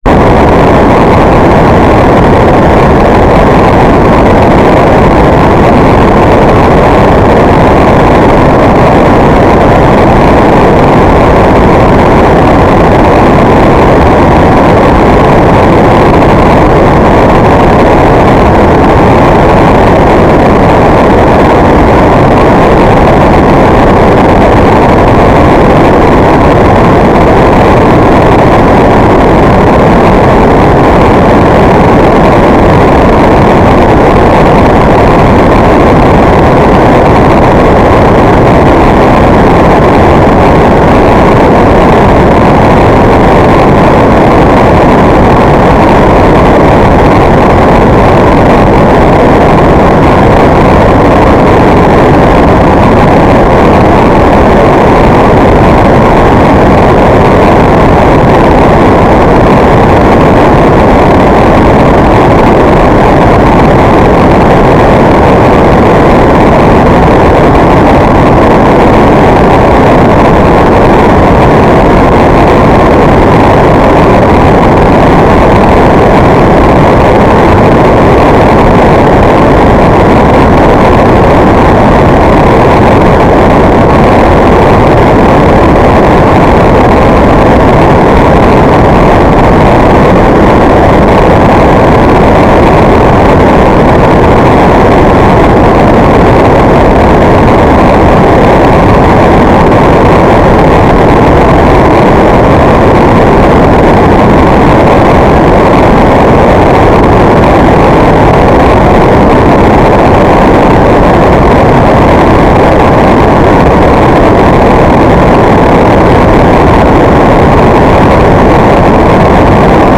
"transmitter_description": "Mode U - FSK1k2",
"transmitter_mode": "FSK",